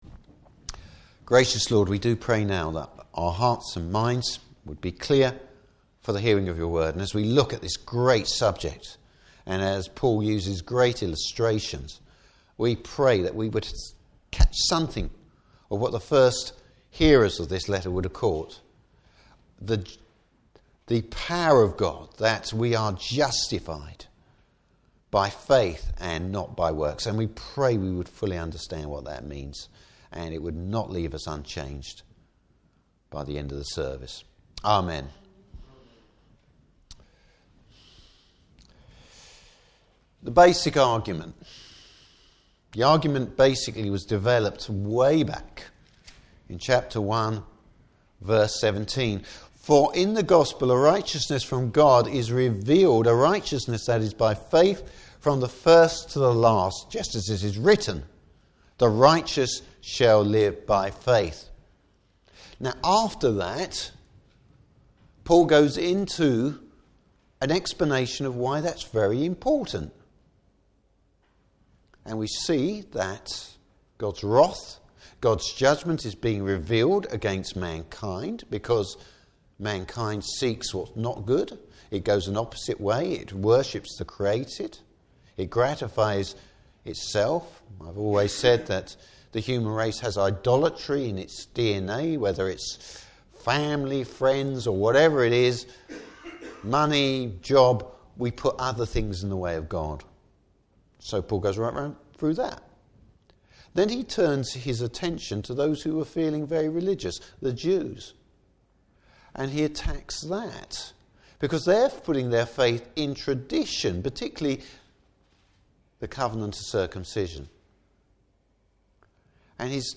Service Type: Morning Service What made Abraham right with God and how does that work?